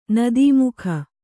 ♪ nadī mukha